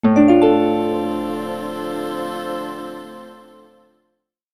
11018 heaven welcome intro ding
fantasy intro magic opening positive starting stinger welcome sound effect free sound royalty free Sound Effects